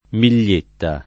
[ mil’l’ % tta ]